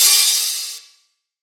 DDW2 OPEN HAT 4.wav